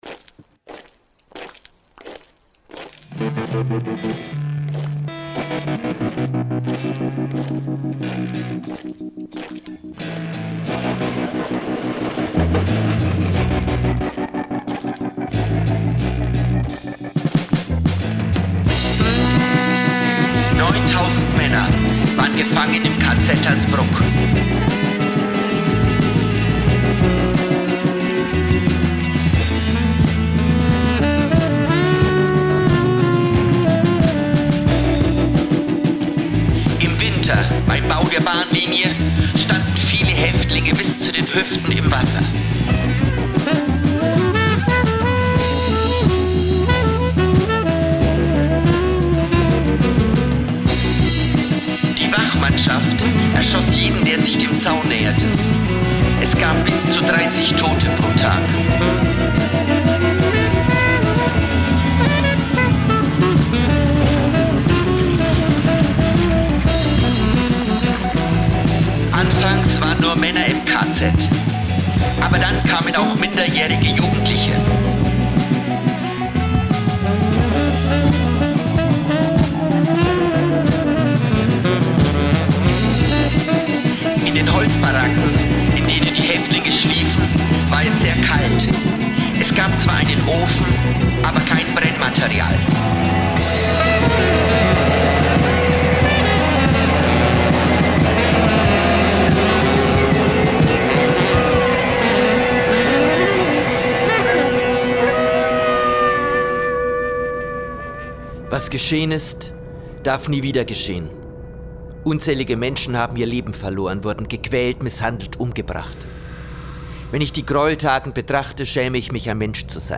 Live aus dem ROsengarten in Hersbruck